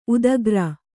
♪ udagra